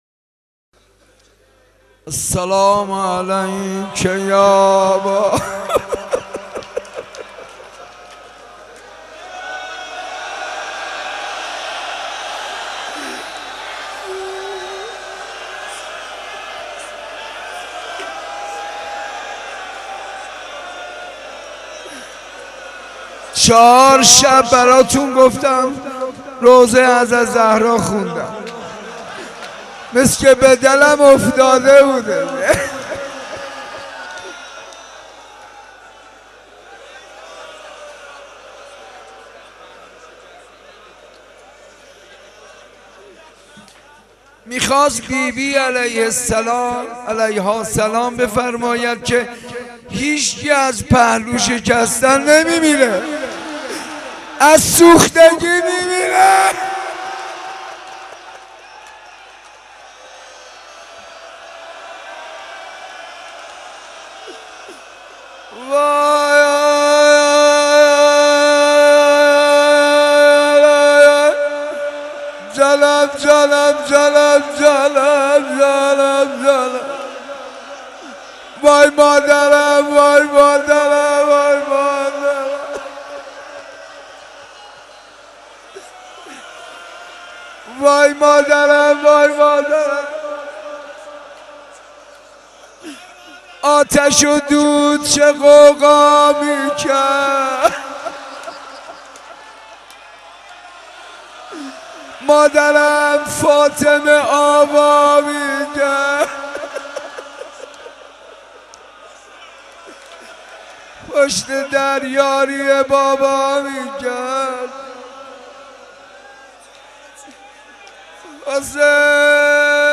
shabe 06 moharram 83 ark.mp3